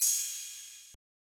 crash2.wav